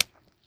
STEPS Pudle, Walk 05, Single Impact.wav